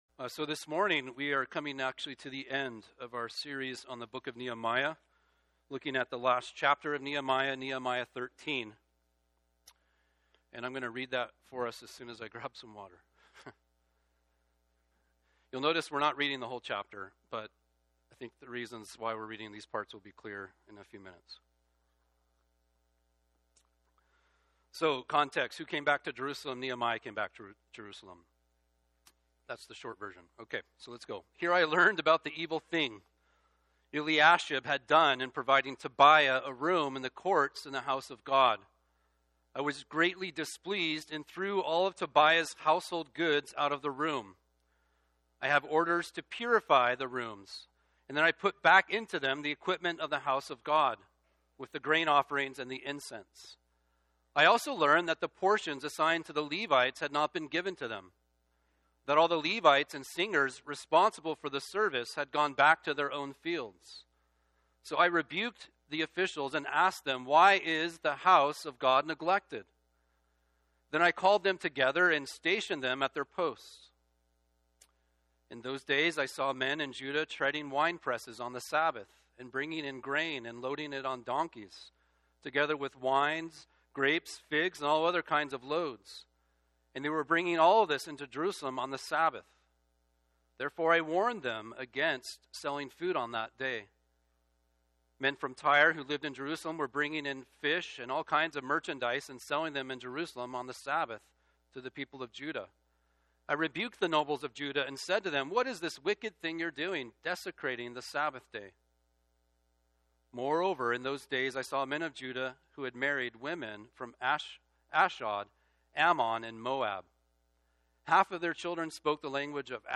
Download Download Reference Nehemiah 13 Sermon Notes Dec 1 sermon slides.ppt Here I learned about the evil thing Eliashib had done in providing Tobiah a room in the courts in the house of God.